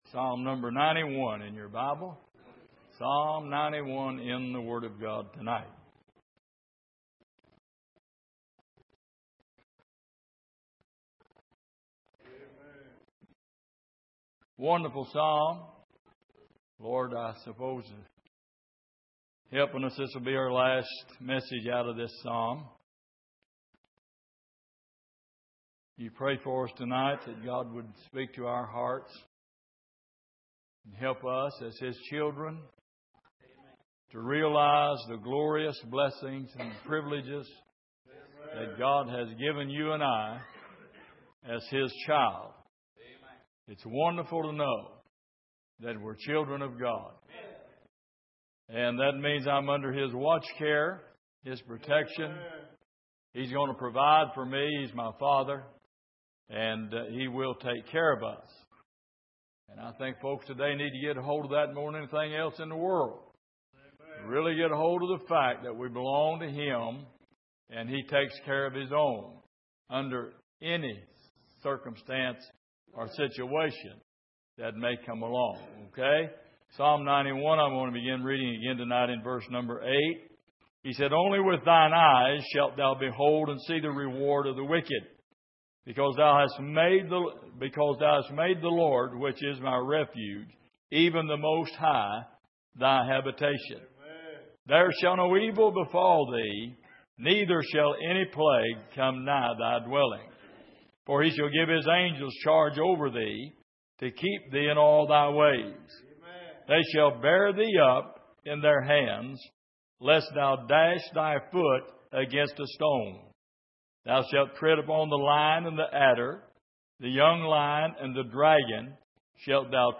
Passage: Psalm 91:8-16 Service: Sunday Evening